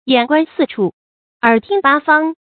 注音：ㄧㄢˇ ㄍㄨㄢ ㄙㄧˋ ㄔㄨˋ ，ㄦˇ ㄊㄧㄥ ㄅㄚ ㄈㄤ